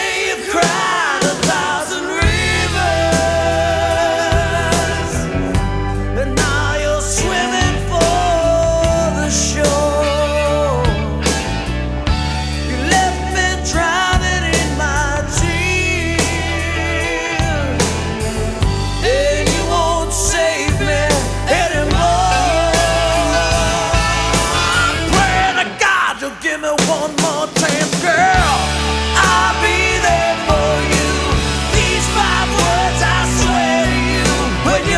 Hard Rock Rock Hair Metal Metal Pop Pop Rock Arena Rock
Жанр: Поп музыка / Рок / Метал